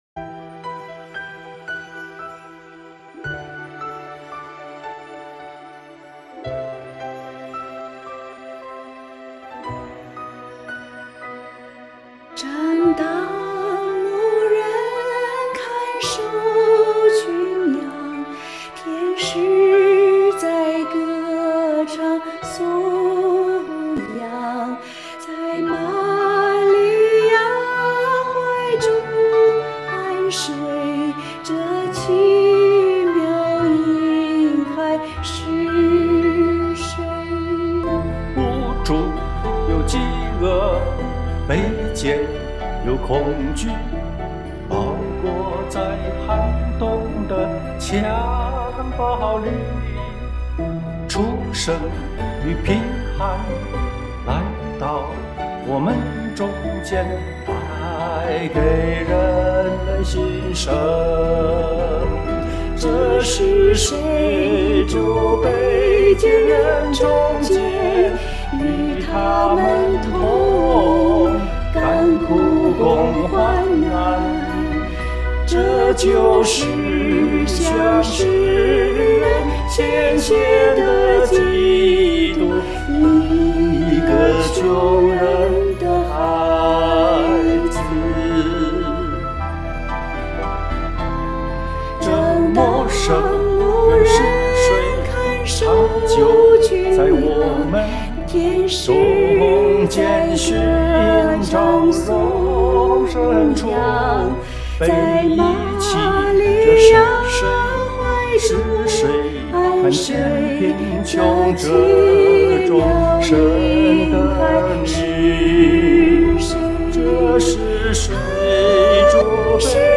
这是一首由两首诗歌编排而成的二重唱歌曲，这两首诗歌分别介绍如下：
并列演唱有极佳的和声效果。